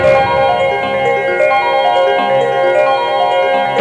Bells Intro Sound Effect
Download a high-quality bells intro sound effect.
bells-intro.mp3